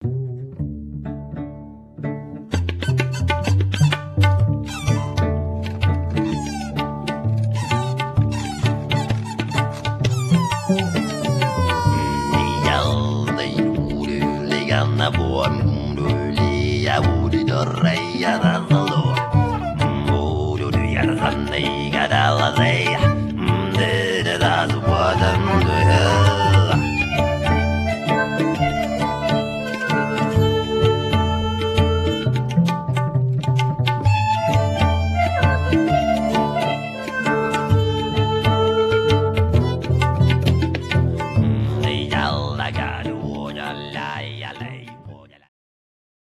wiolonczela, sarangi, ¶piew alikwotowy
`ud, fujara słowacka, lira korbowa, drumla, duduk, głosy
tabla, bendir
baglama, saz, tanbur, ¶piew gardłowy
lira korbowa, ney, kaval
suka biłgorajska, tanpura, głosy